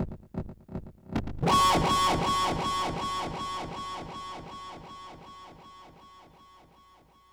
guitar02.wav